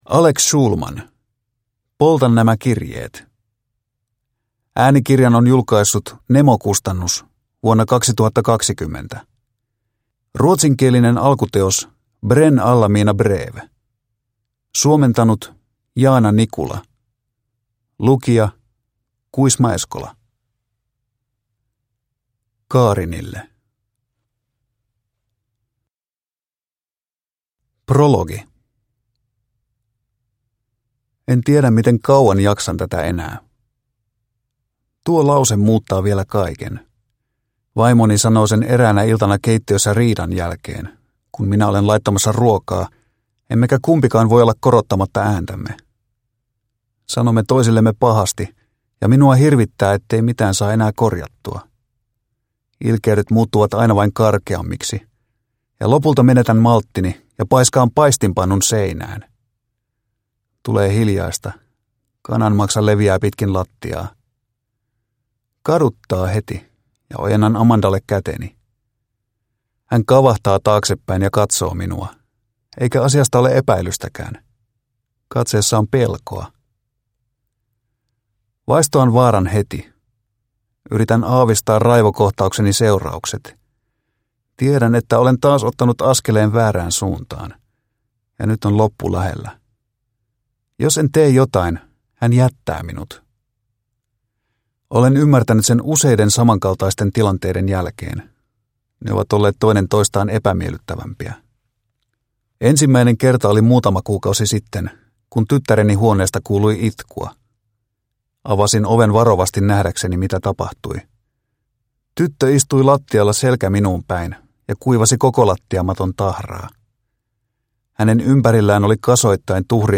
Polta nämä kirjeet – Ljudbok – Laddas ner